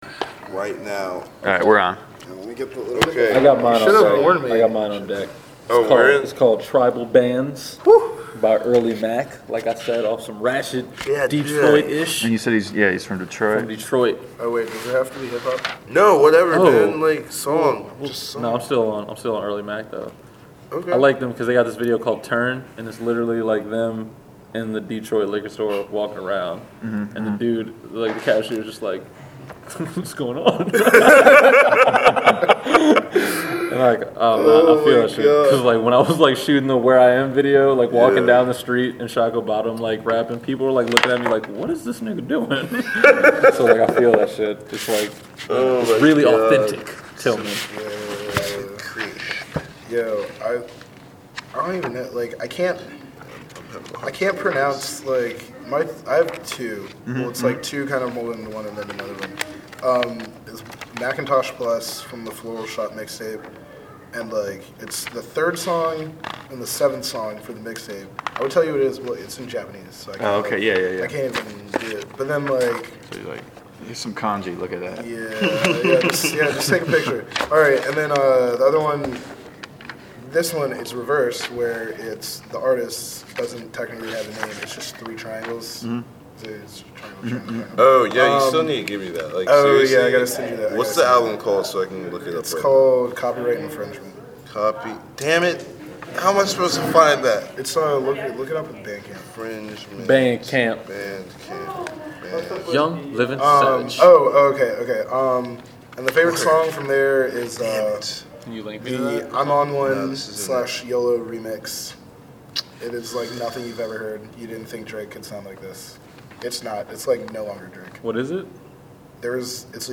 WM SHHO oral history interview, 2013